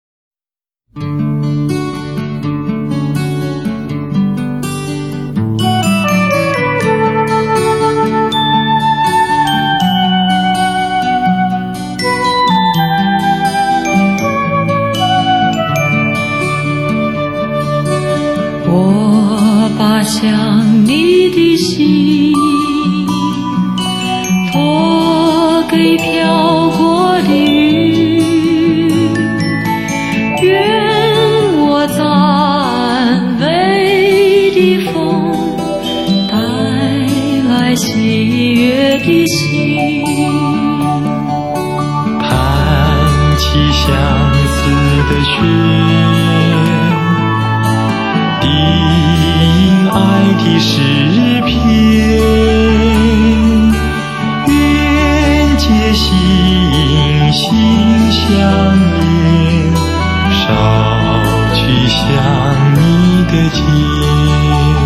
專輯類別：國語流行、絕版重現